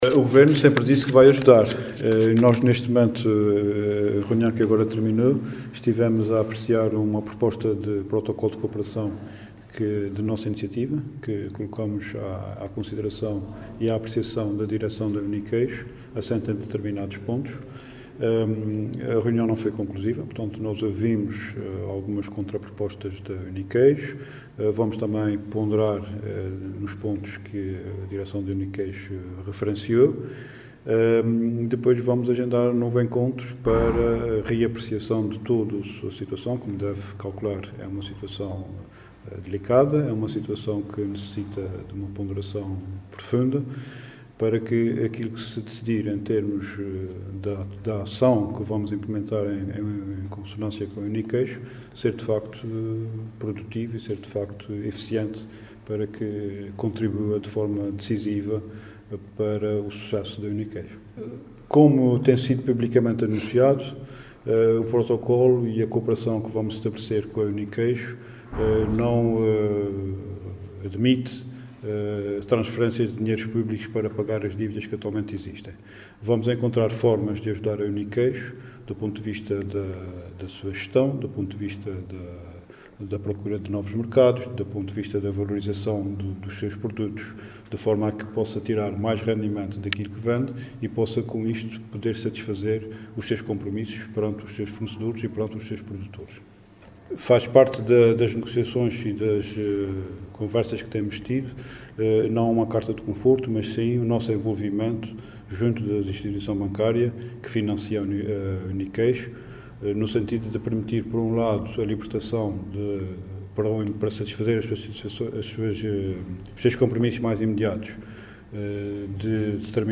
“Vamos encontrar formas de ajudar a Uniqueijo do ponto de vista da sua gestão, do ponto de vista de procura de novos mercados e do ponto de vista da valorização dos seus produtos”, afirmou Luís Neto Viveiros em declarações aos jornalistas.